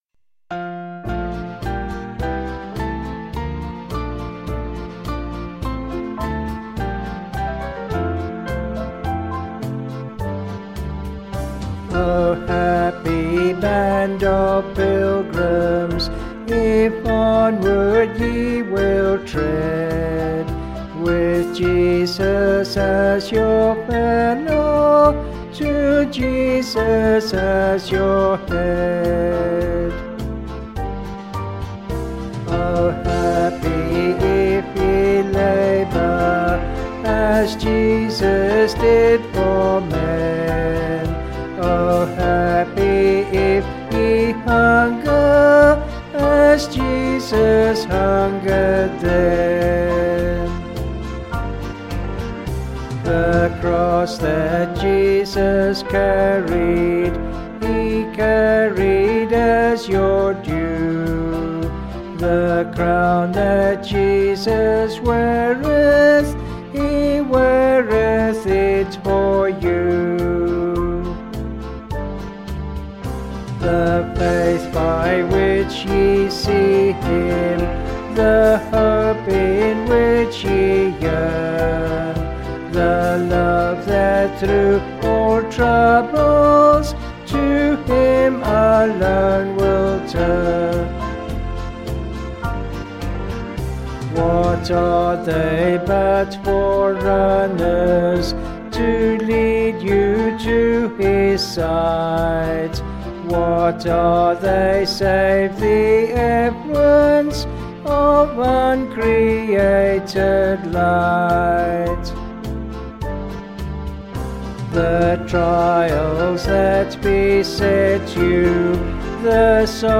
Vocals and Band   264.7kb Sung Lyrics